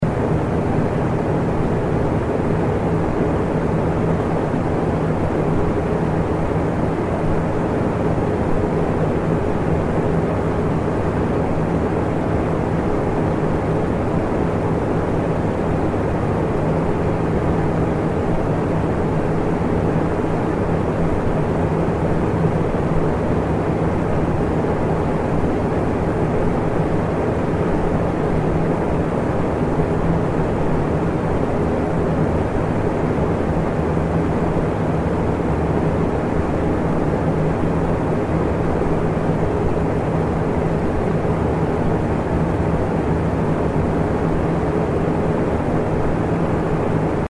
На этой странице собраны различные звуки работающего вентилятора: от тихого гула компьютерного кулера до мощного потока воздуха из бытового прибора.
Вентилятор как белый шум для сна